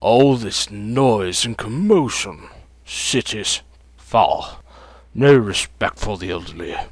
vs_falgerno_cuss.wav